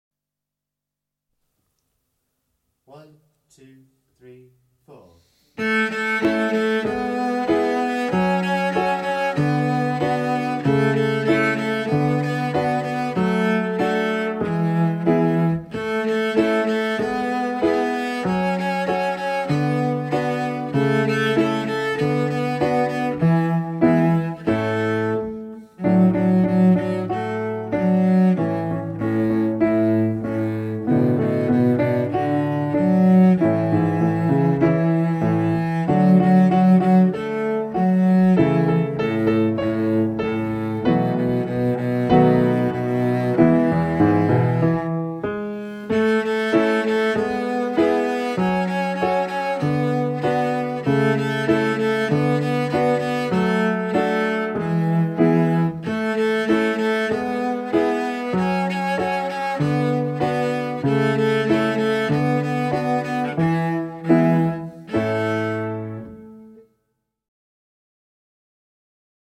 29 Ready, steady, go, now! (Cello)